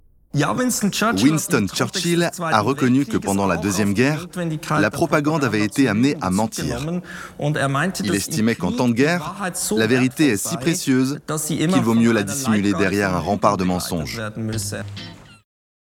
Voice Over - La vérité selon Churchill
- Basse